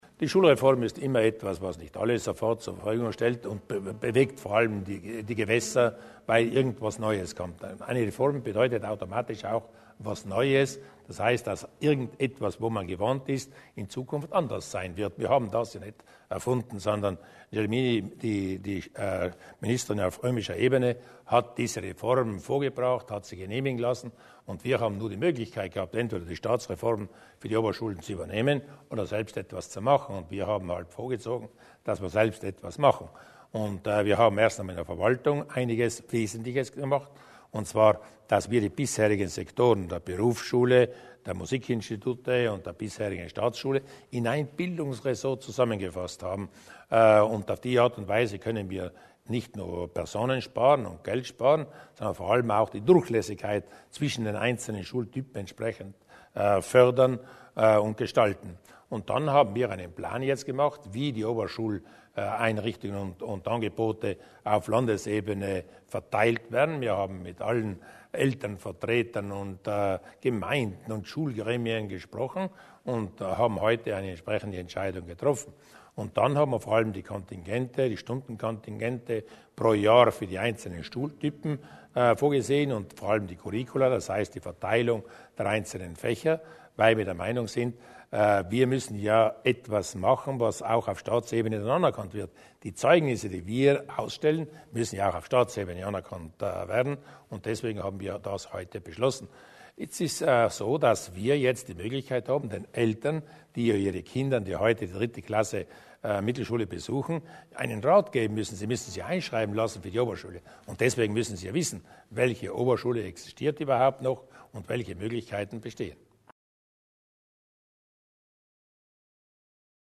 Landeshauptmann Durnwalder zur Oberstufen-Reform